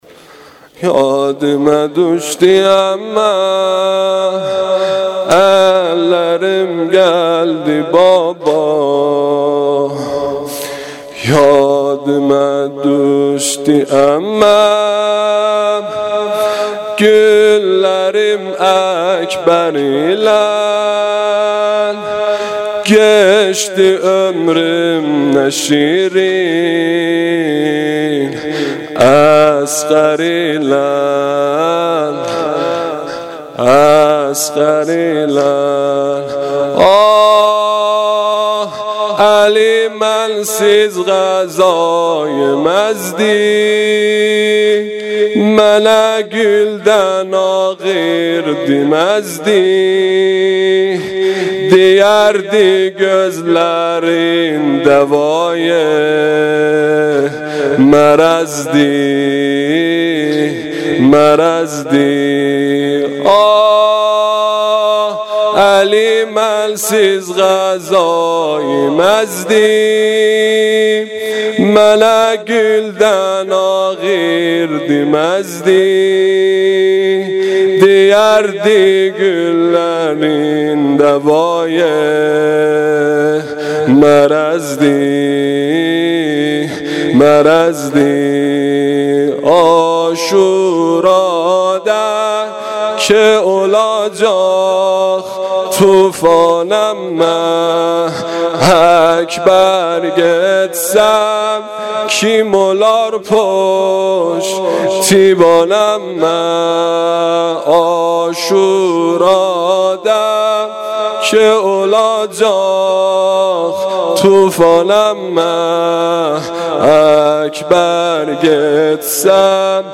واحد سنگین شب سوم محرم الحرام 1395